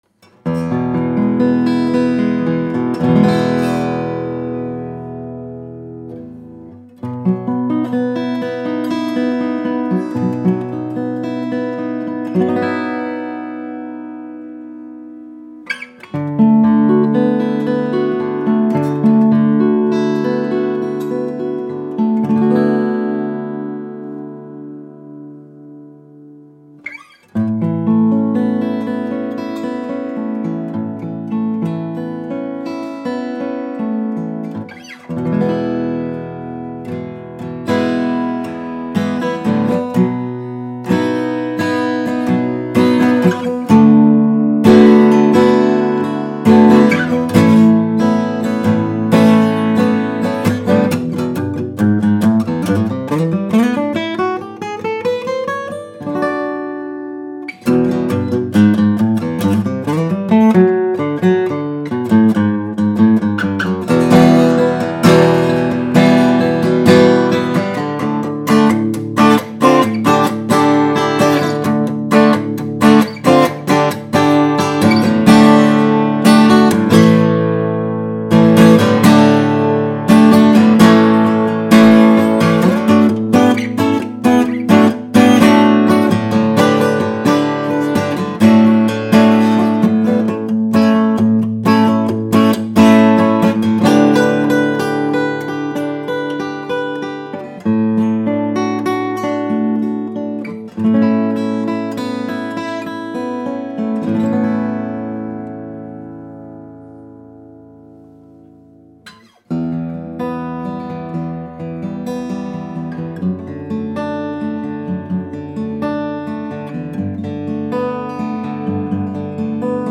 She sounds like a young vintage right out of the case.
Whether you’re playing in a studio, on stage, or at home, this baby sings with a powerful low-end, shimmering highs, and a beautifully open midrange.
Incredible overtones and something about the neck feels so right.